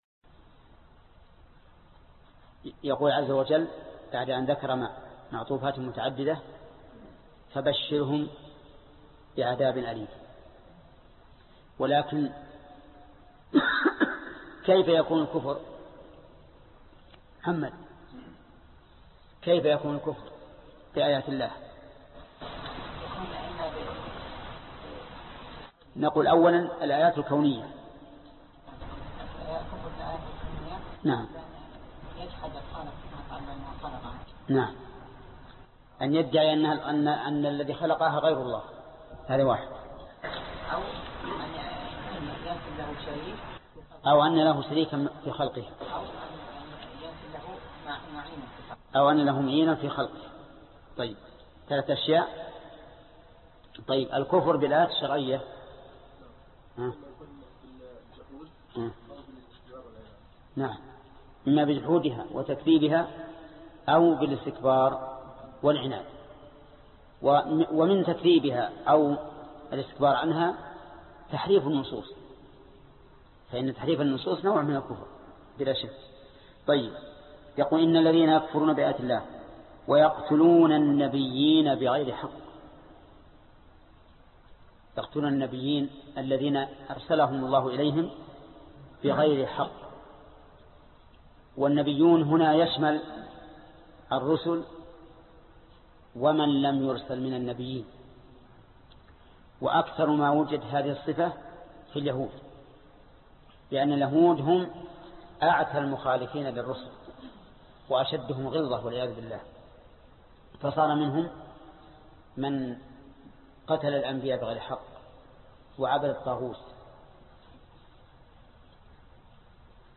الدرس 45 الآية رقم 21 (تفسير سورة آل عمران) - فضيلة الشيخ محمد بن صالح العثيمين رحمه الله